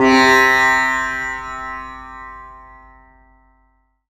It features a mixture of field and studio recordings and programming for
Tabla,
Oriental Percussion,